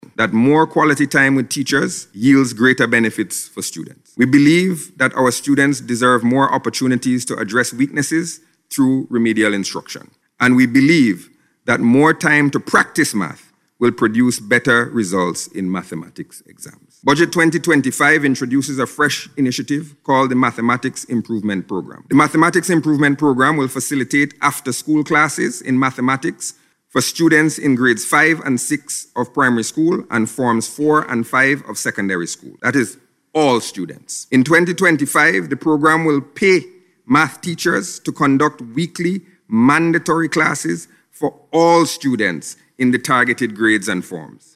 While delivering the 2025 budget address last week, Minister Gonsalves said budget 2025 will introduce a fresh initiative called the Mathematics Improvement Programme for all students.